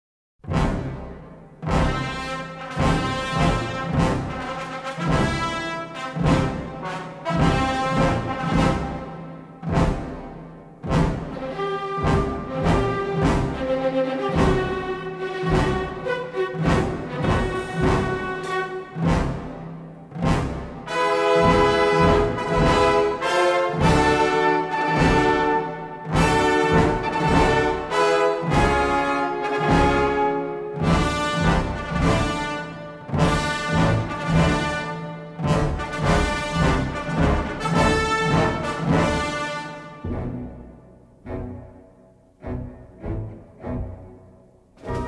suspenseful Nazi march